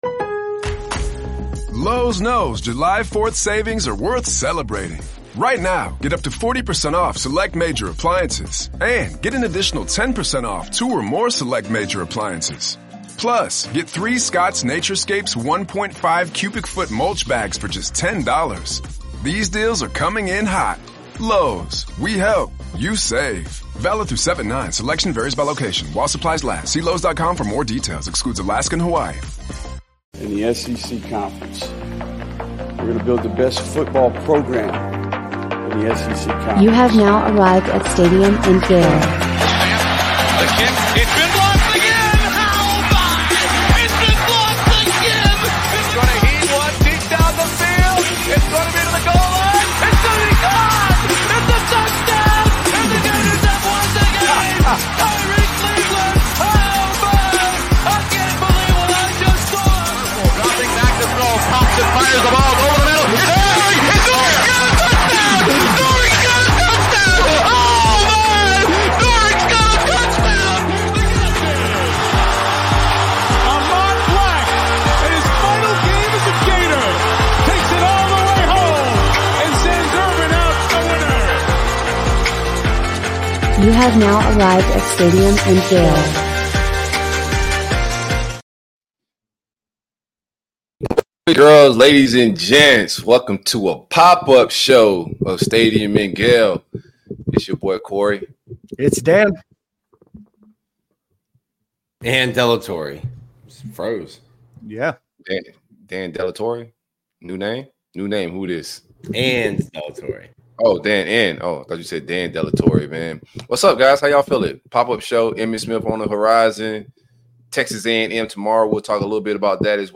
Exclusive: Interview with Emmitt Smith
The Stadium and Gale guys sit down with Florida Gators and NFL Legend Emmitt Smith for an exclusive interview.